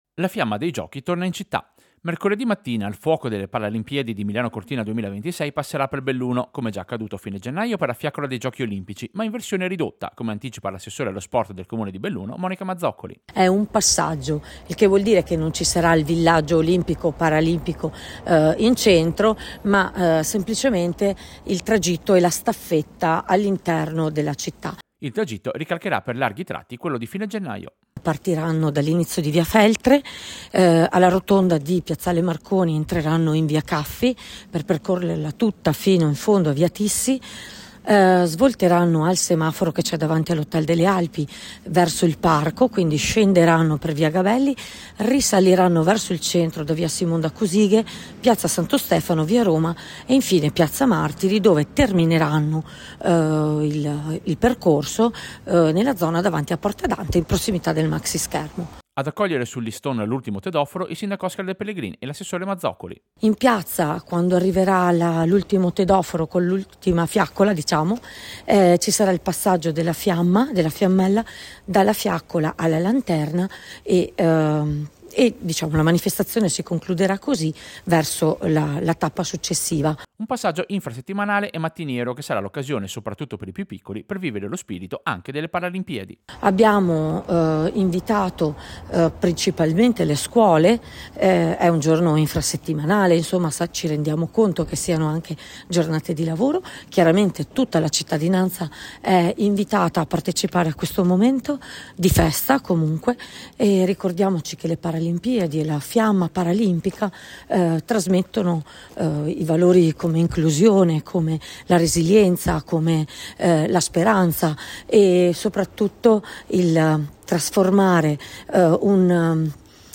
Servizio-Passaggio-fiamma-paralimpica-Belluno.mp3